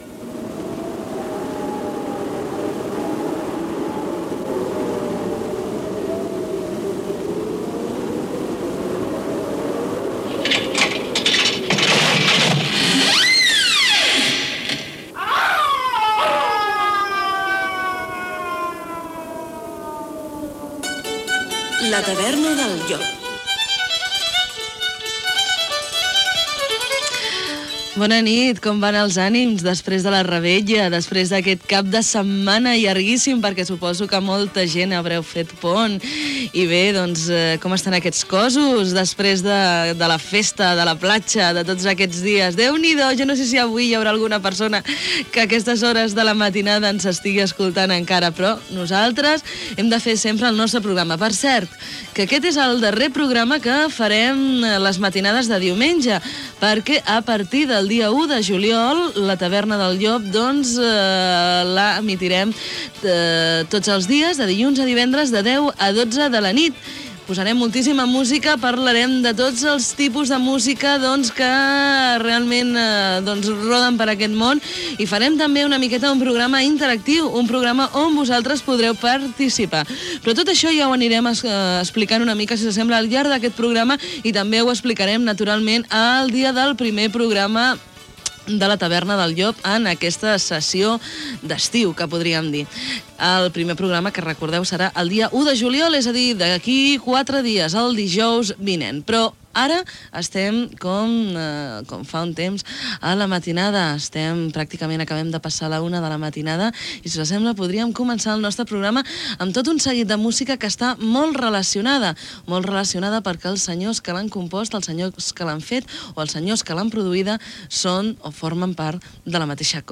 Gènere radiofònic Musical